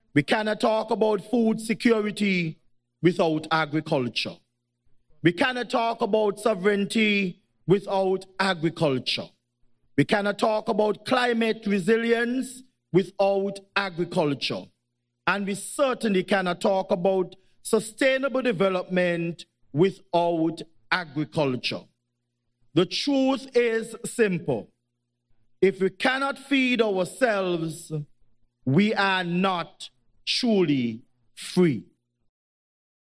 Echoing similar sentiments was Deputy Prime Minister, the Hon. Geoffrey Hanley, delivering the keynote address on behalf of Prime Minister, the Hon. Dr. Terrance Drew.